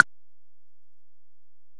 Perc (3).wav